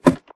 ladder2.ogg